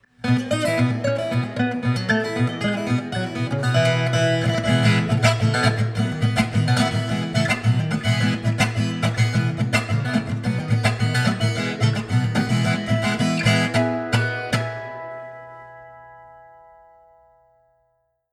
Viola Caipira Regis Bonilha
Une clarté et un sustain d’ enfer.
Pagode de viola
viola2.mp3